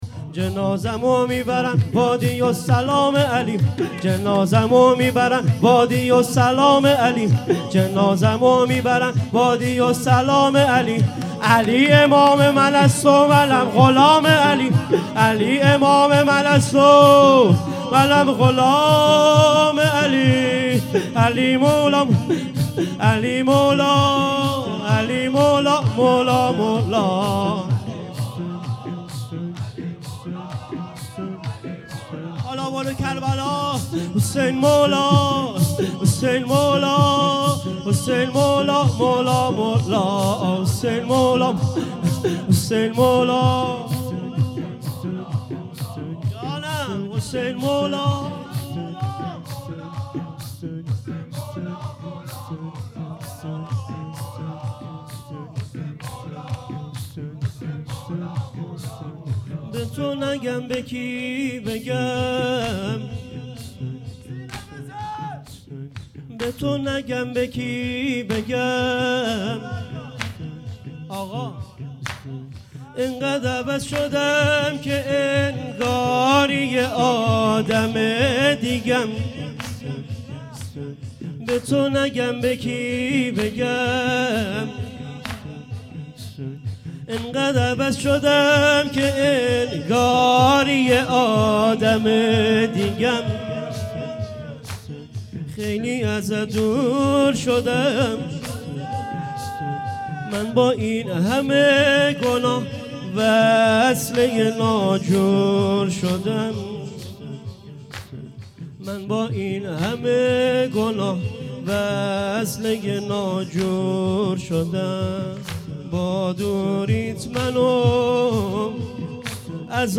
شور
مراسم فاطمیه